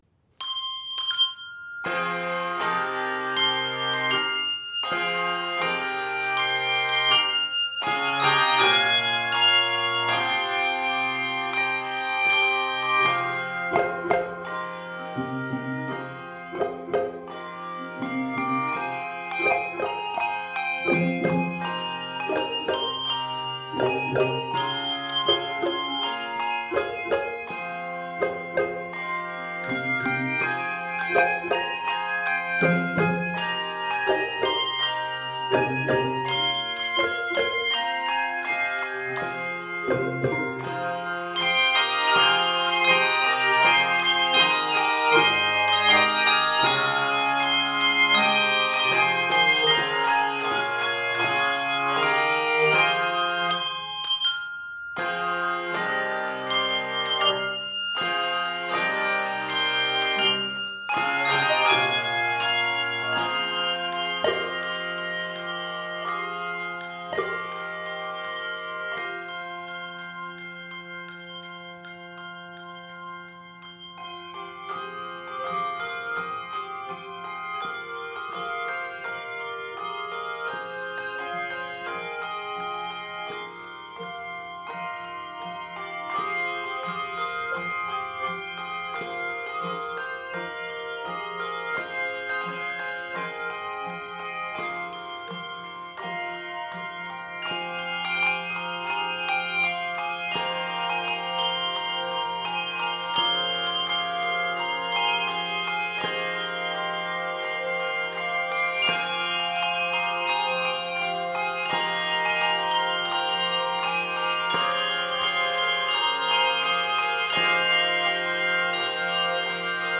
Key of f minor.